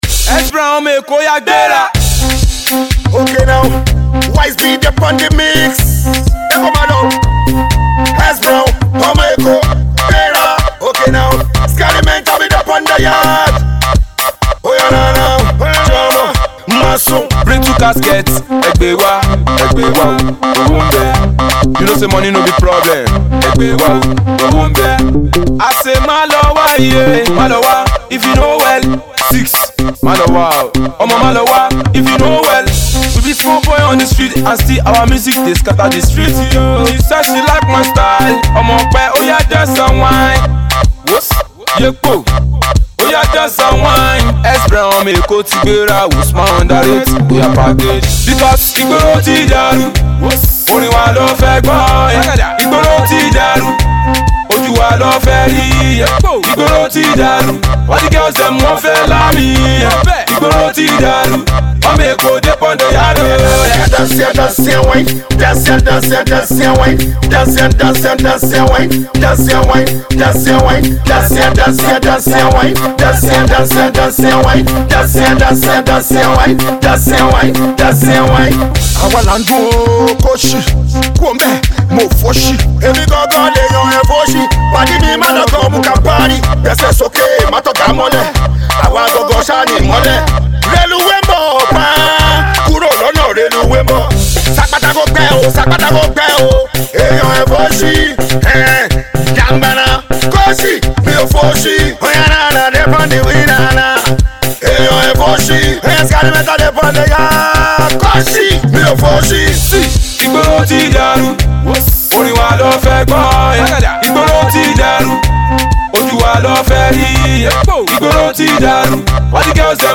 street hub